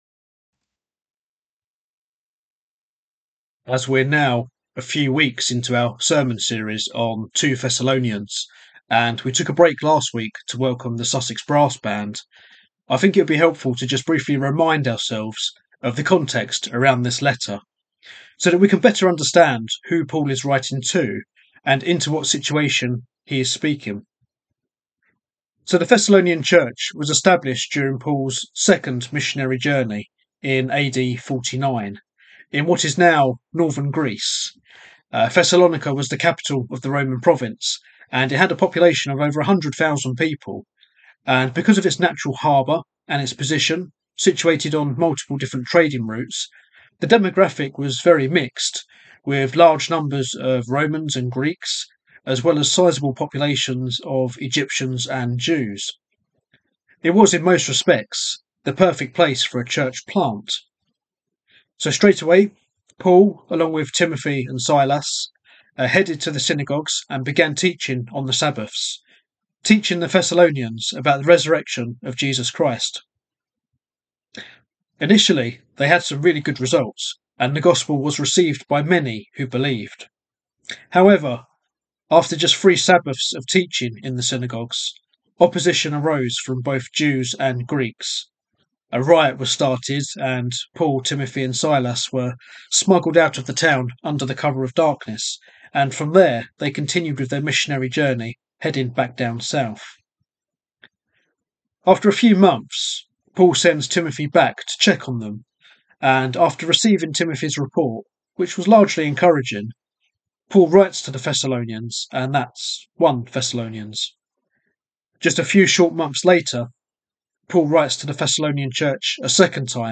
2-Thessalonians-Sermon-mp3cut.net_.m4a